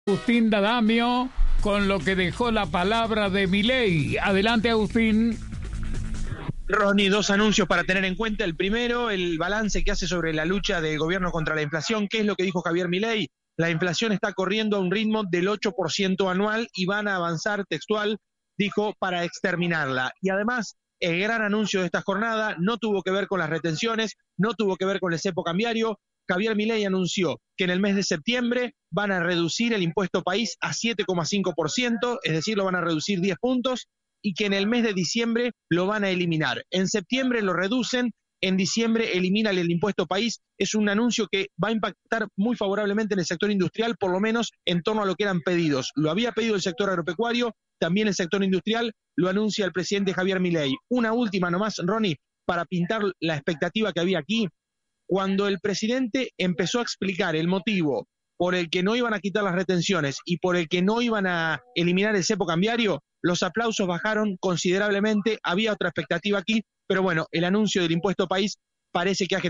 Audio. Milei anunció la eliminación del Impuesto País para diciembre en La Rural
El presidente Javier Milei aseguró que su "compromiso" es eliminar las retenciones y prometió el levantamiento del cepo al dólar, aunque evitó dar precisiones de fechas para su concreción al brindar un discurso en la inauguración de la Exposición Rural.